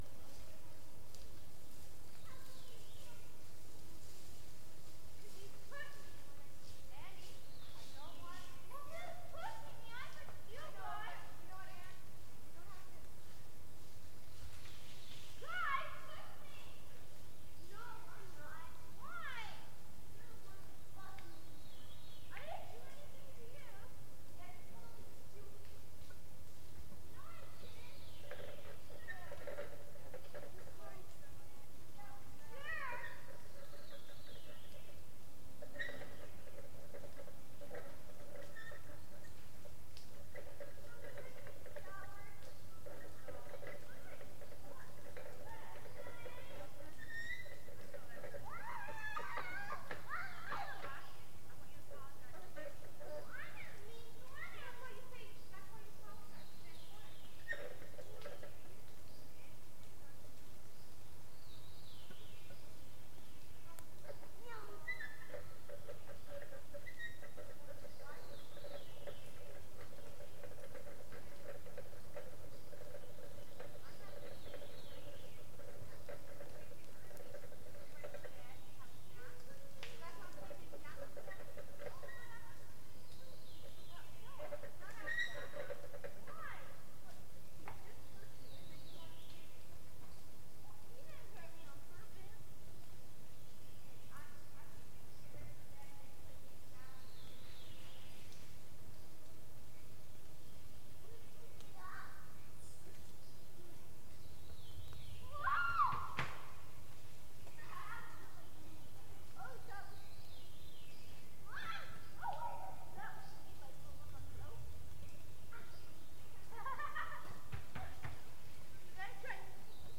recording of Awenda national park in Canada, with birds, chipmunks and children playing.